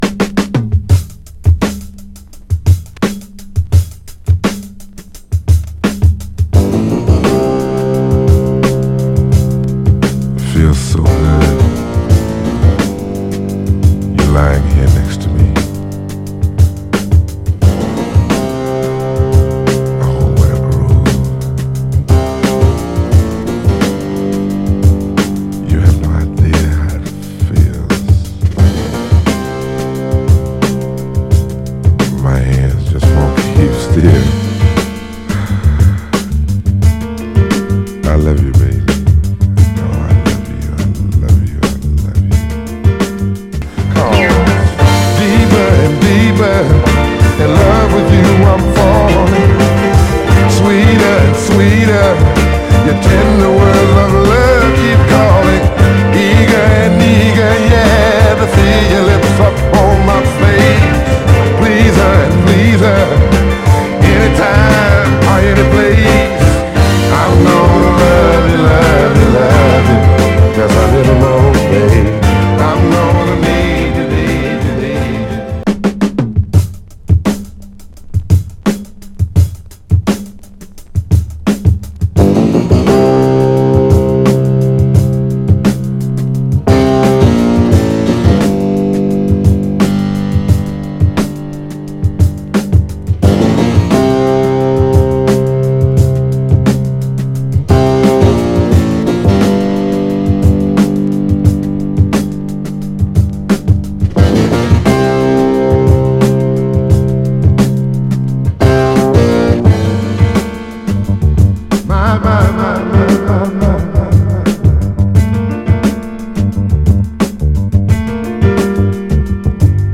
冒頭を飾るタイトなドラムブレイクは、使用例を挙げるとキリが無い超定番ブレイク・クラシックです！
ドラムだけでなく、その後のピアノ・リフも多くサンプリングされています。
ファットな音質の7インチ・シングルで是非どうぞ。
※試聴音源は実際にお送りする商品から録音したものです※